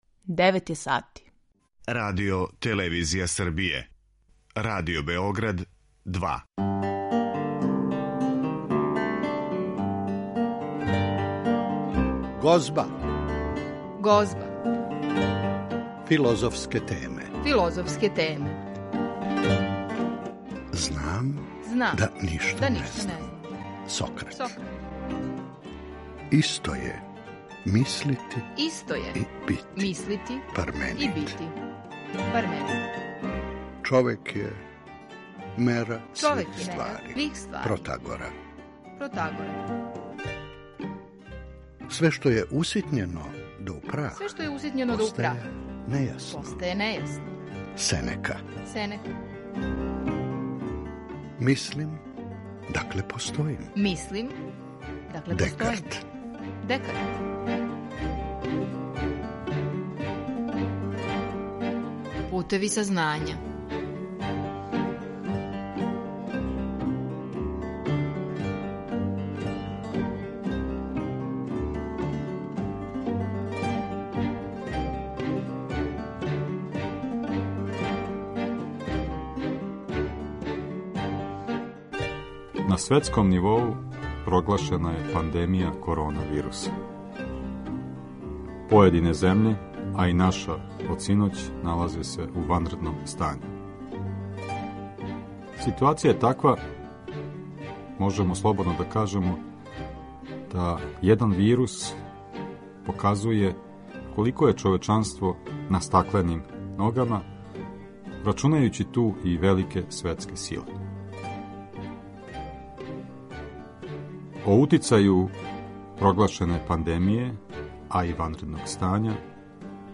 Филозофија је мисао свога времена, како је писао Хегел, па се и у Гозби промишљало време кроз разговоре са филозофима, друштвеним теоретичарима и лекарима. У емисији ћете чути кратке одломке тих разговора.